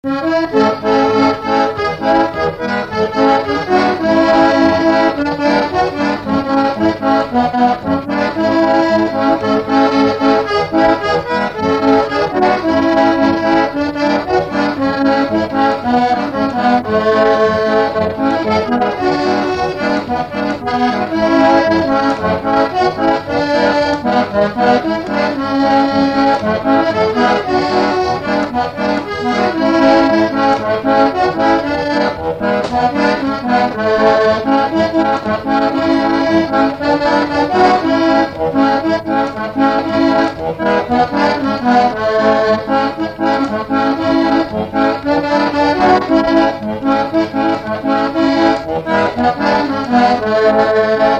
Montage de deux ségas mauriciens
Instrumental
danse : séga